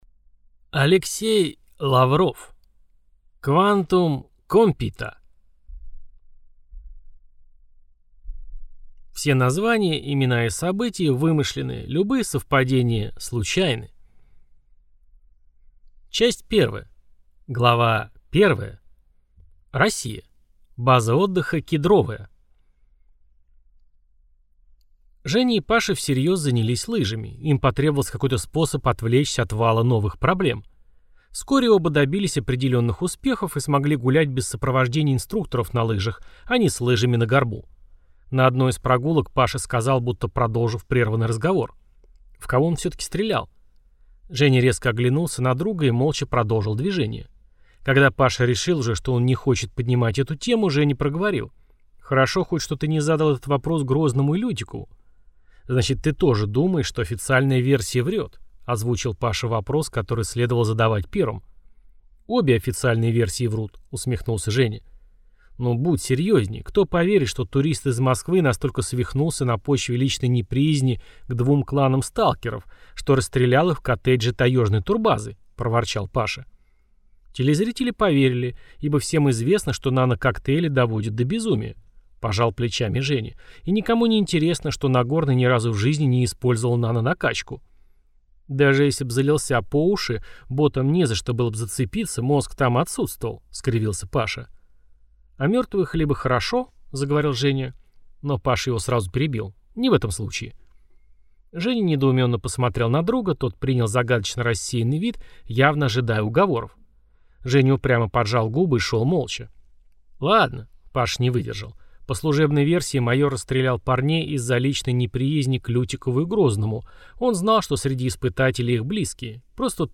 Aудиокнига Quantum compita